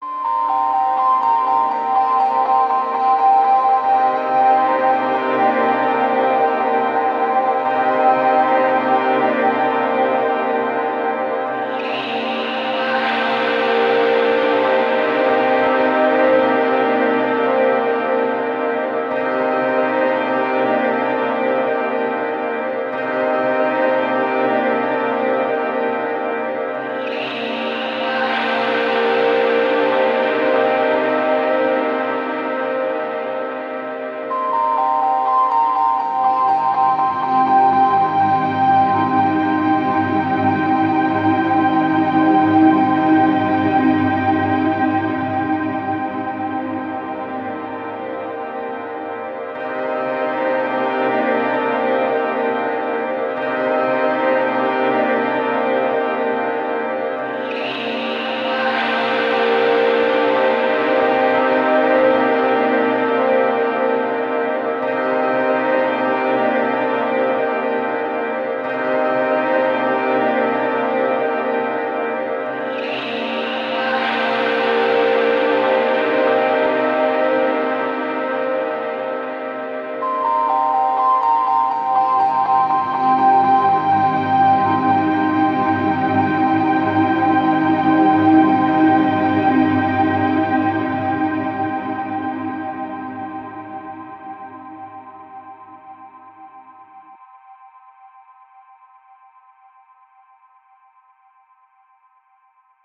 Ambient, Electronic, Soundscape, Drone